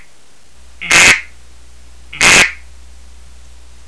quack.wav